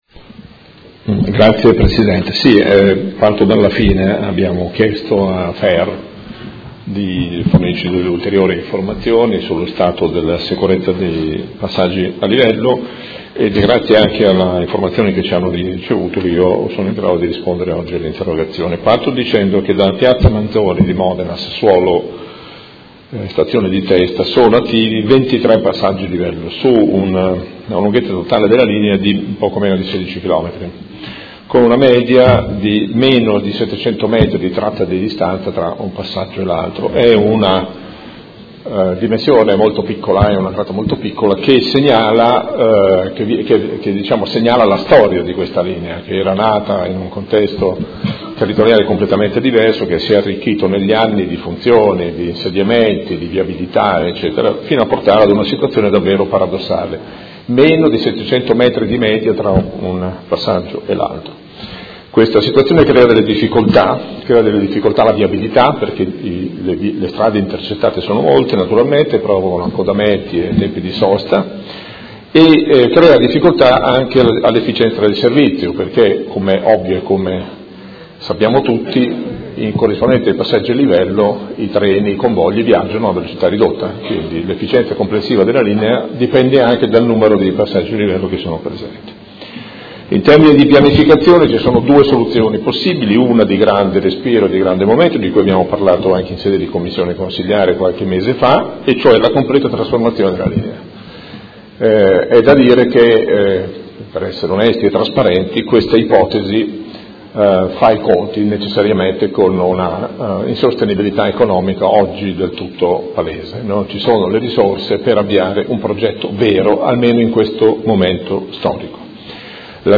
Seduta del 26/10/2017. Risponde a interrogazione del Consigliere Pellacani (FI) avente per oggetto: Sono sicuri i passaggi a livello di “Gigetto”?